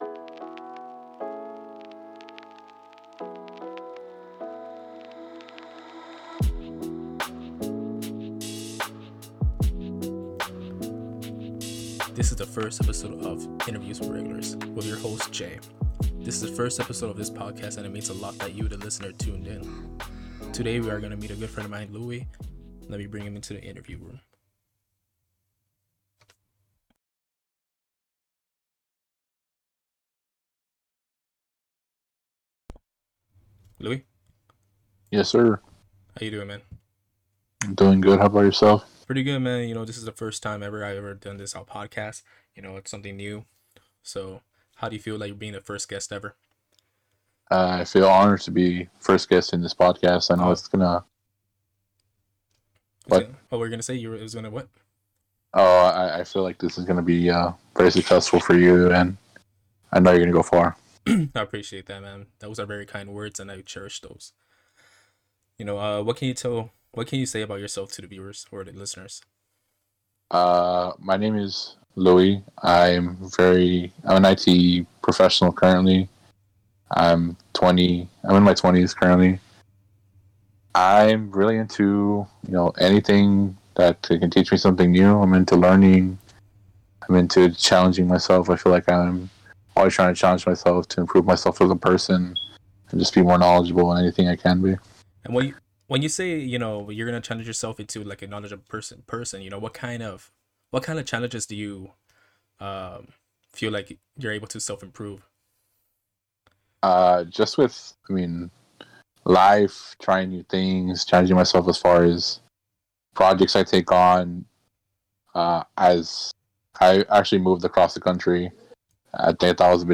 This interview took place on Discord.